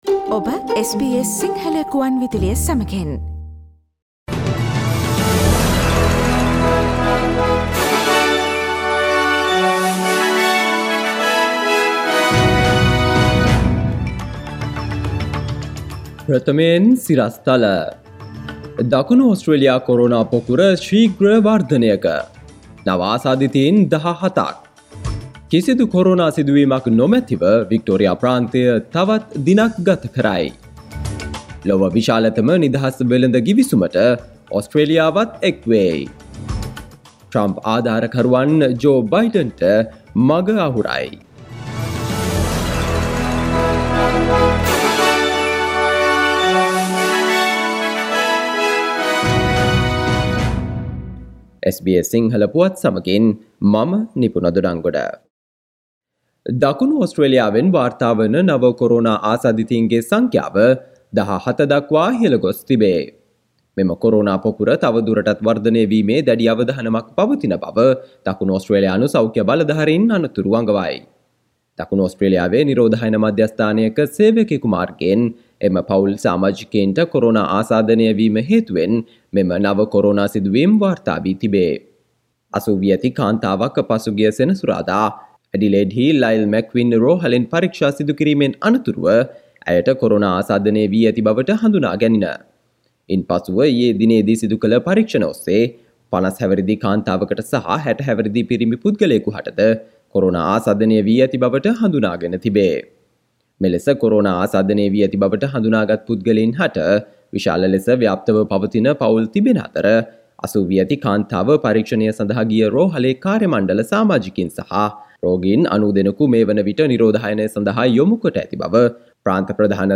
Daily News bulletin of SBS Sinhala Service: Monday 16 November 2020
Today’s news bulletin of SBS Sinhala radio – Monday 16 November 2020.